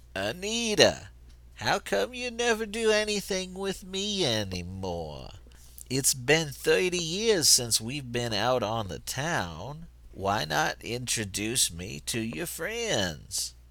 I don't know if fate wants to bring him back, but trying to recreate the voice sounded fun.
Though, it sure sounds like he's given up smoking? oy gevalt!
All in all the excersize was straightforward, I just took the best sounding take from 3 tries.